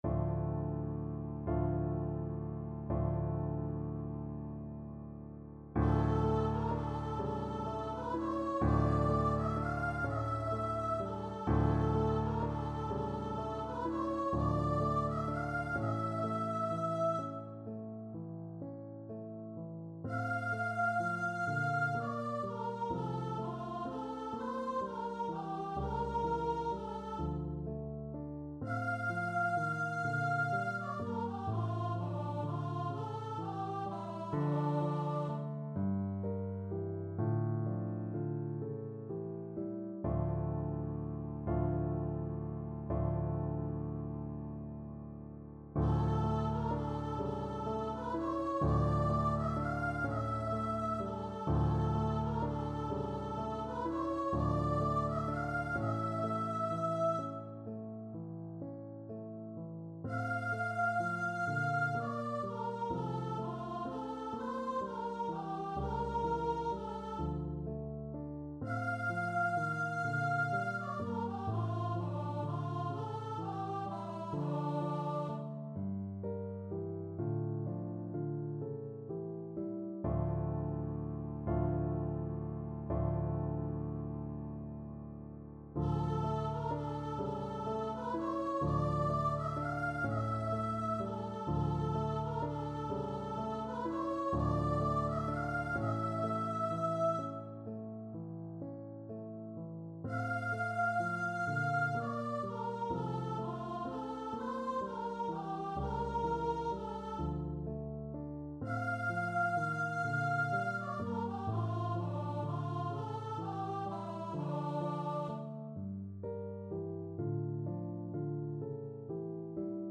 Free Sheet music for Voice
6/8 (View more 6/8 Music)
D5-F6
F major (Sounding Pitch) (View more F major Music for Voice )
. = 42 Andante con moto (View more music marked Andante con moto)
Classical (View more Classical Voice Music)